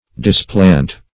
Displant \Dis*plant"\, v. t. [imp.